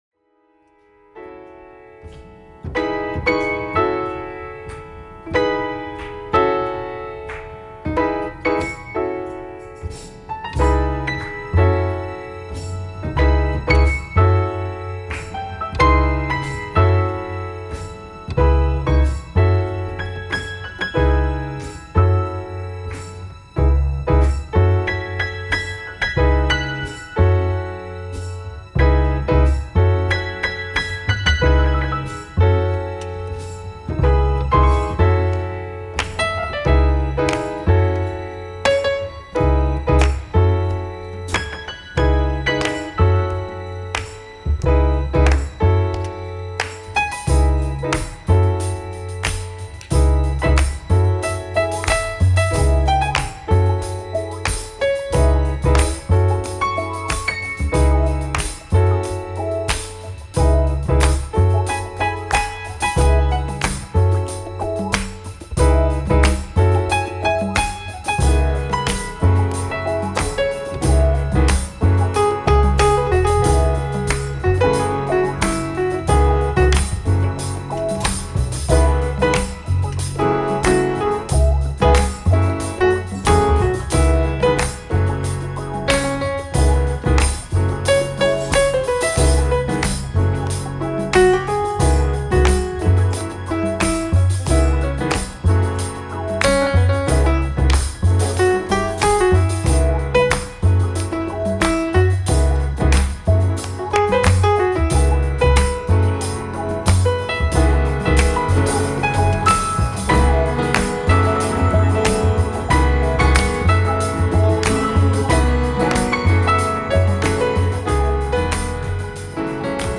ダイジェスト音源